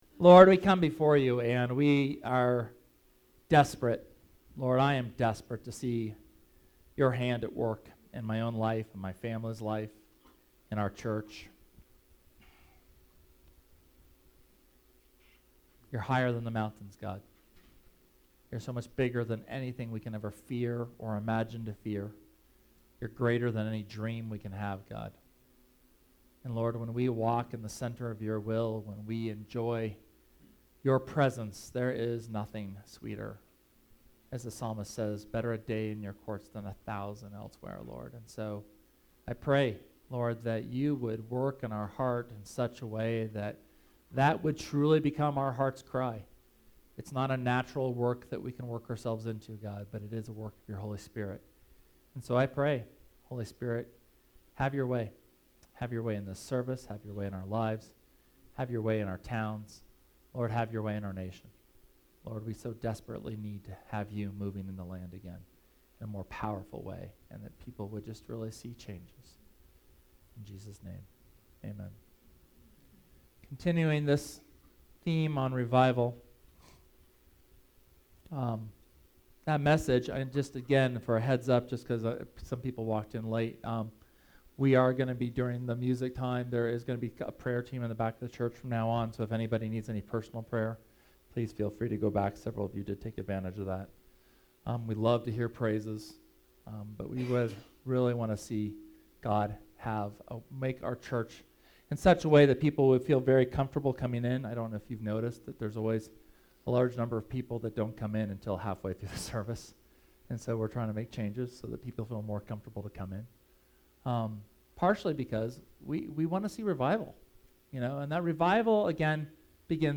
SERMON: Revival (7) – Church of the Resurrection